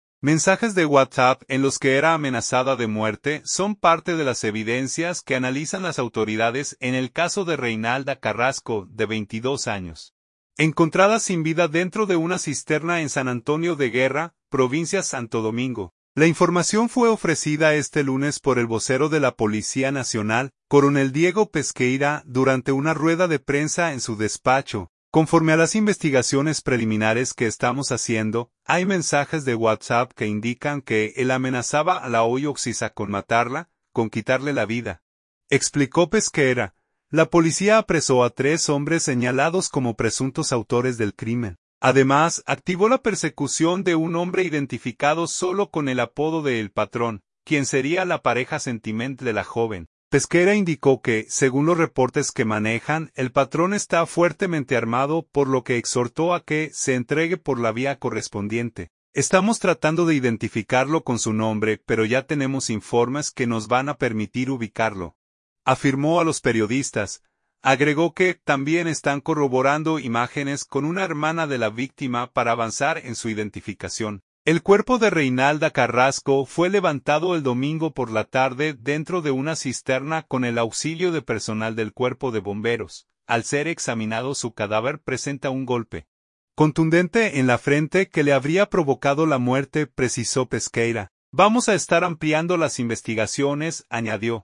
durante una rueda de prensa en su despacho.